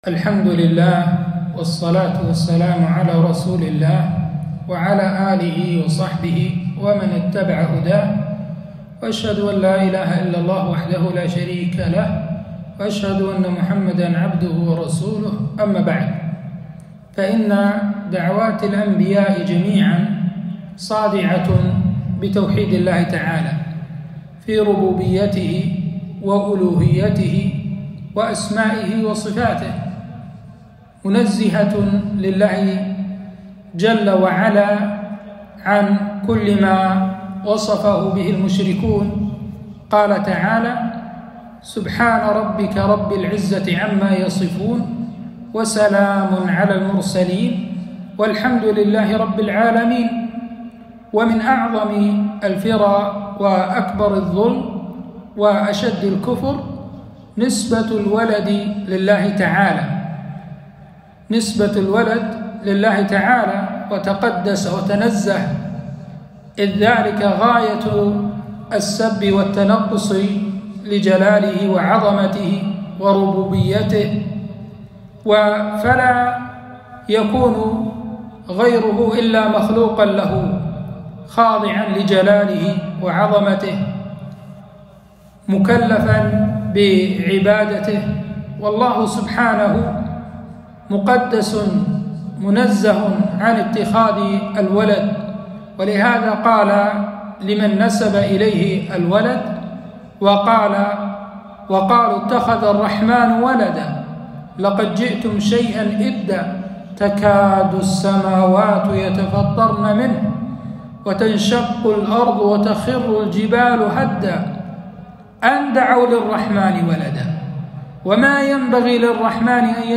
كلمة - التحذير من الاحتفال بعيد النصارى